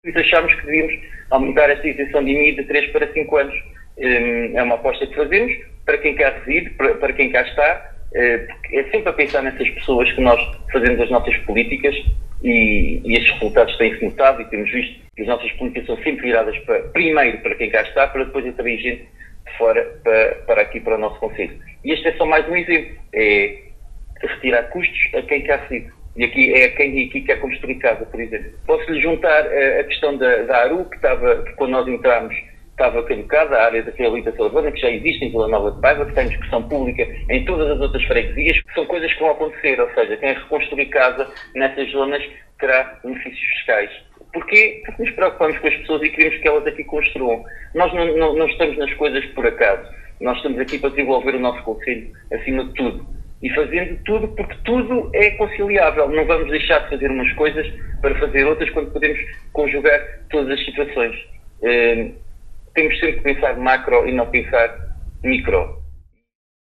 Paulo Marques, Presidente do Município de Vila Nova de Paiva, em declarações à Alive FM, diz que esta isenção representa uma aposta a pensar nos residentes e para quem vem de fora e que se queira fixar no concelho. O Autarca falou também da ARU (ÁREAS DE REABILITAÇÃO URBANA), que visa a reabilitação urbana, em execução na sede do concelho e que está em discussão pública nas freguesias.
Paulo-Marques-IMI-Isencao.mp3